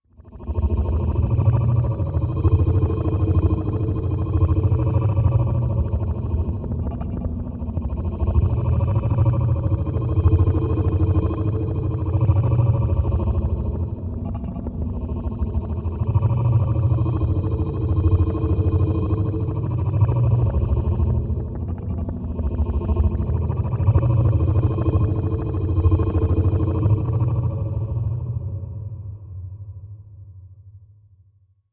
Movement Slower Lower Electric Voice Shimmers Back And Forth